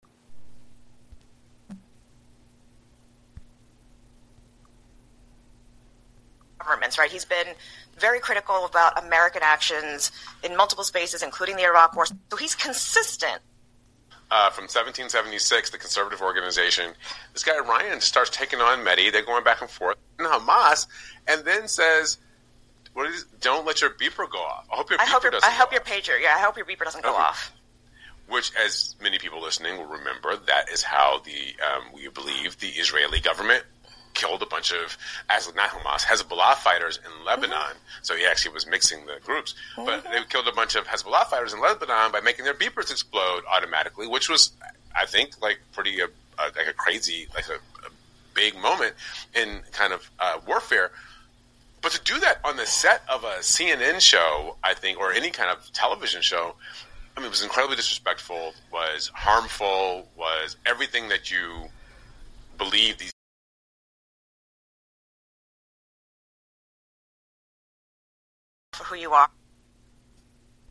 反対にリラックス気味の腹言語の英語の発音の例として、CNNの男性の低めの、太い豊かな響きの発音があります。